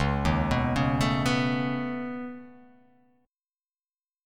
Db7sus2#5 chord